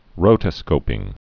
(rōtə-skōpĭng)